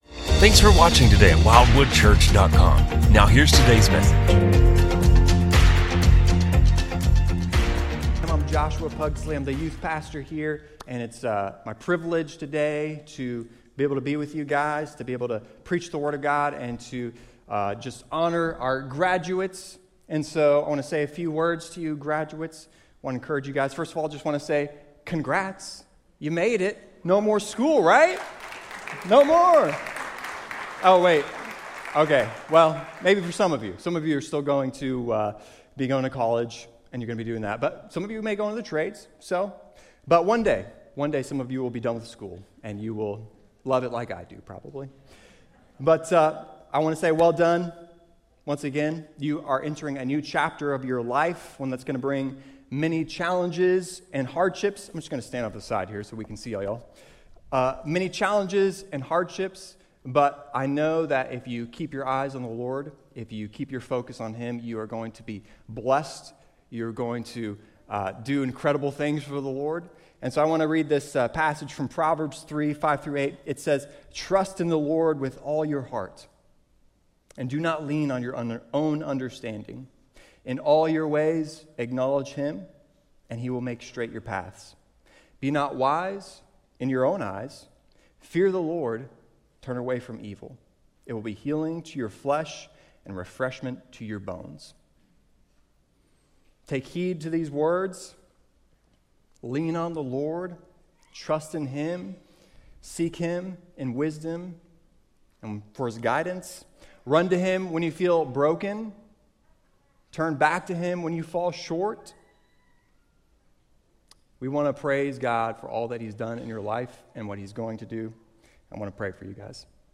A message from the series "Wisdom From Above." We are not in control of our destiny as the world would have us believe. Rather God is sovereign over all aspects of our lives.